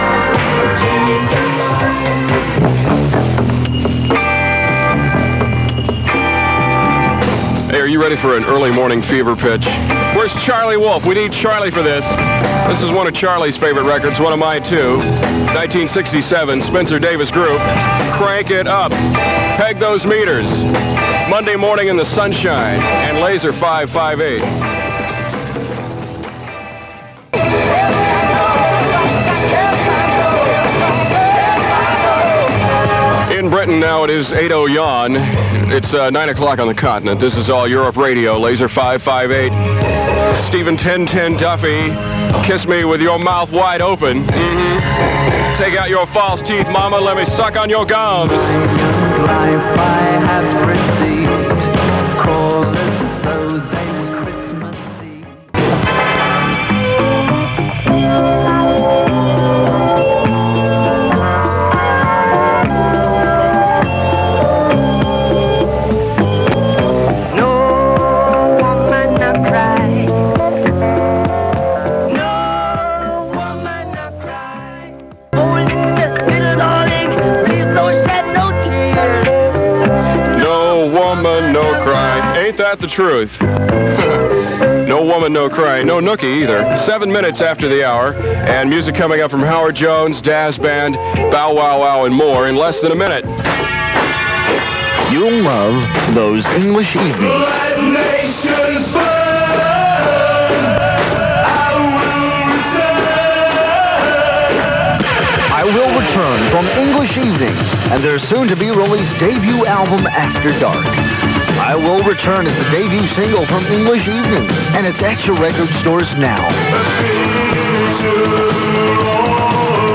These were taped off-air on the Laser ship, the mv Communicator, on a cassette recorder.